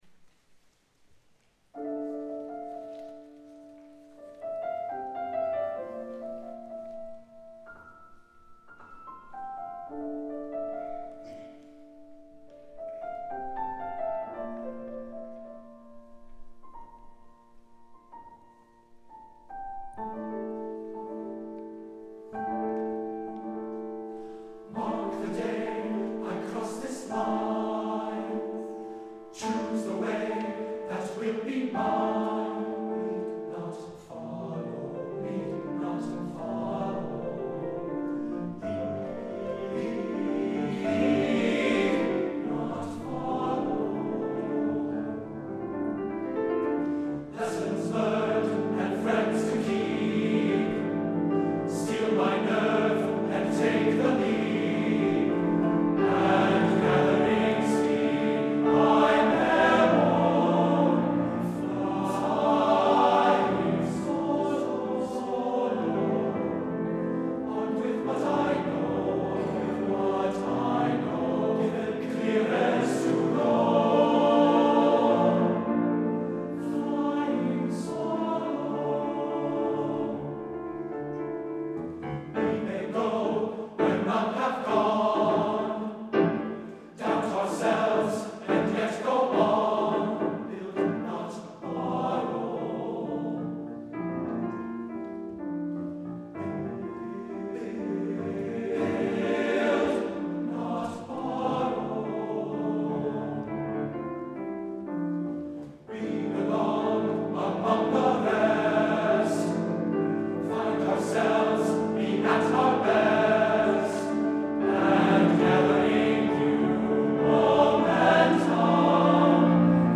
TBB Voices with Piano
• Tenor
• Piano
Studio Recording
Ensemble: Tenor-Bass Chorus
Key: F major
Tempo: Freely (h = 65)
Accompanied: Accompanied Chorus